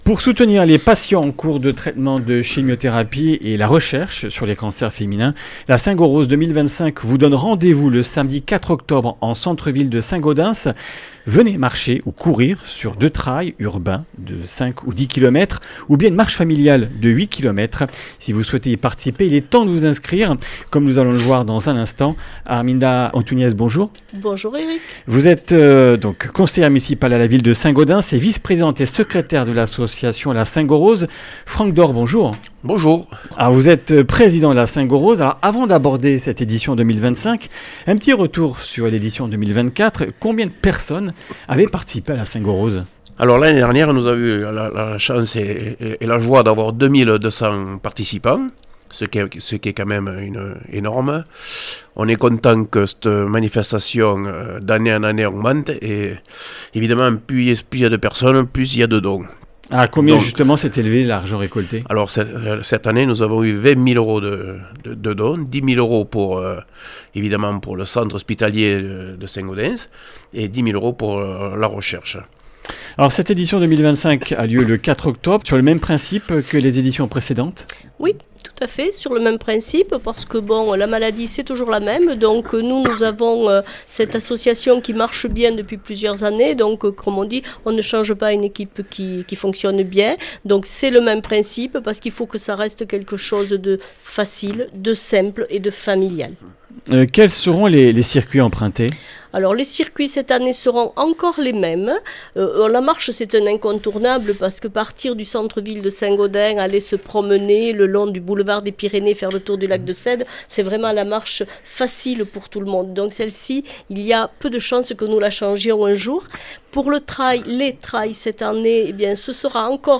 Comminges Interviews du 26 août